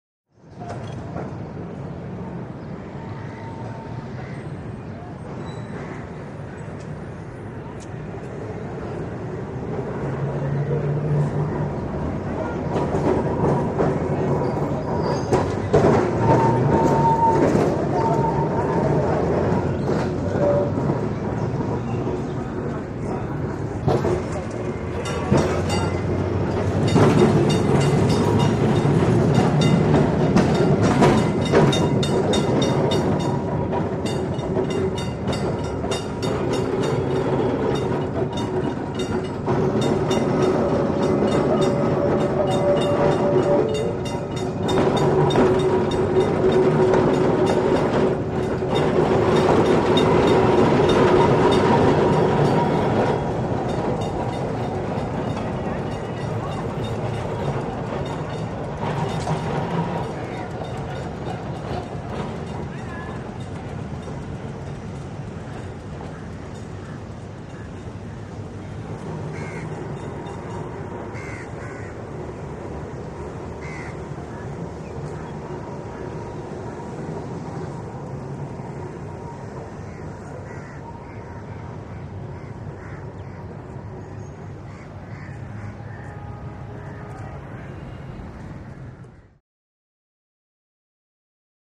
By; Tram Passes By And Clangs Its Bells.